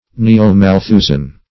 Neo-Malthusian \Ne`o-Mal*thu"sian\, a.